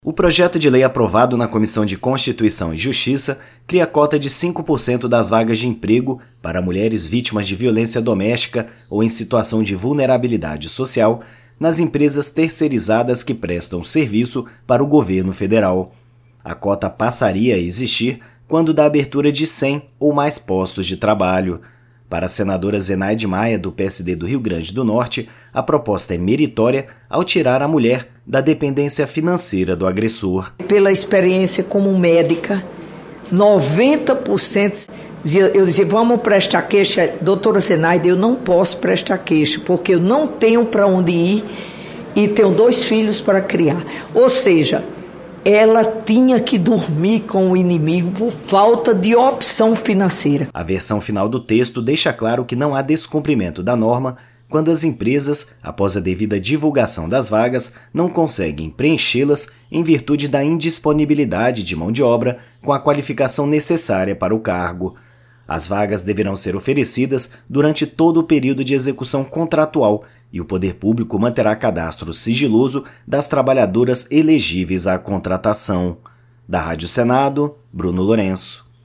A relatora, senadora Zenaide Maia (PSD-RN), diz que iniciativa é meritória ao tirar a mulher da dependência financeira do agressor.